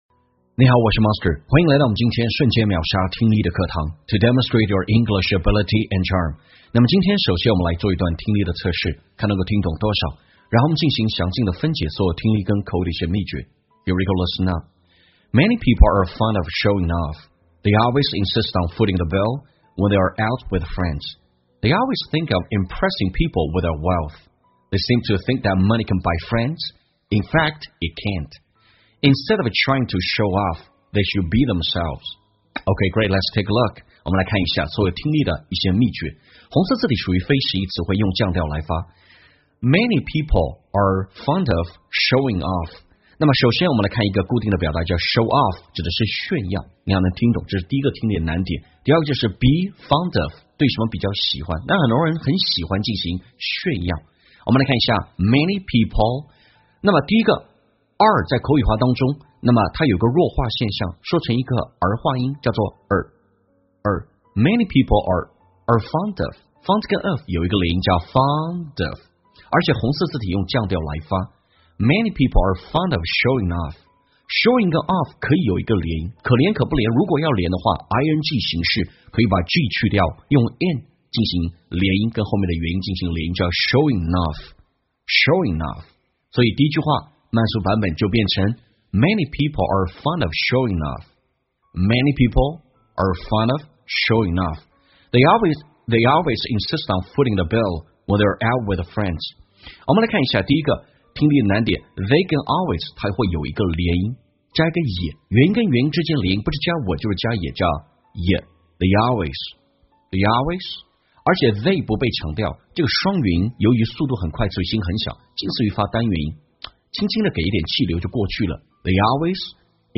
在线英语听力室瞬间秒杀听力 第606期:你还在精致穷吗的听力文件下载,栏目通过对几个小短句的断句停顿、语音语调连读分析，帮你掌握地道英语的发音特点，让你的朗读更流畅自然。